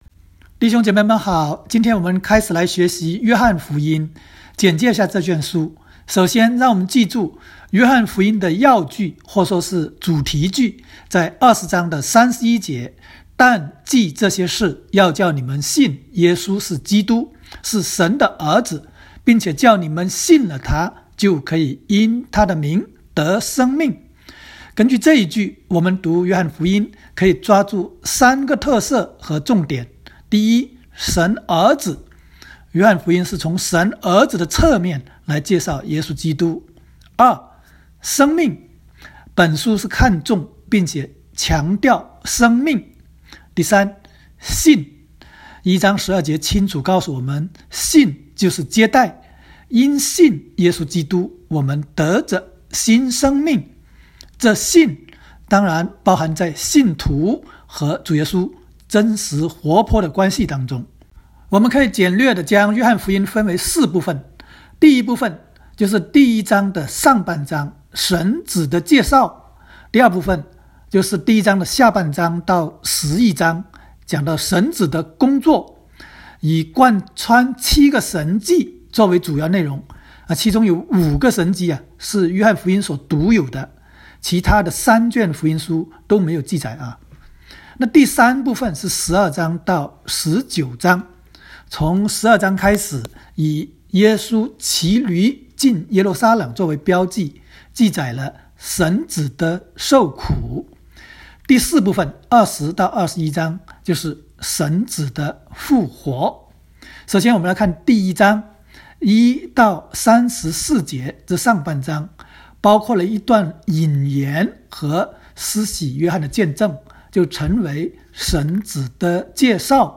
约01（讲解-国）.m4a